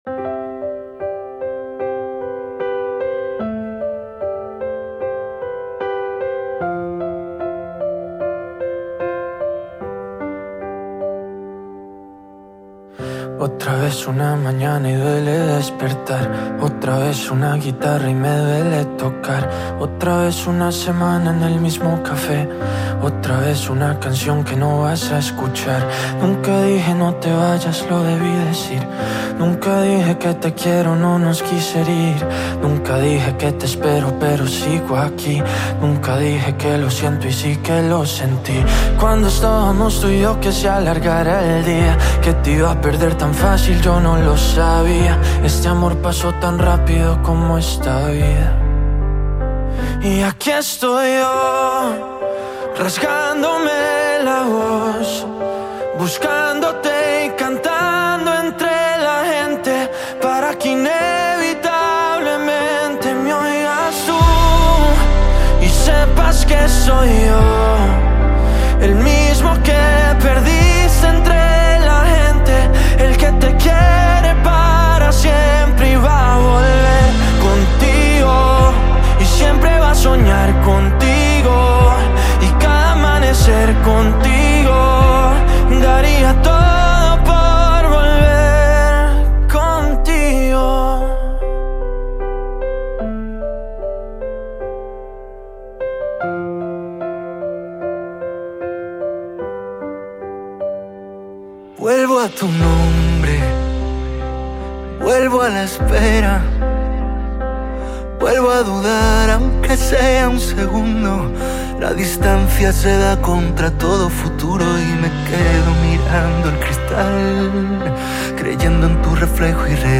Brilliant Colombian latin pop celebrity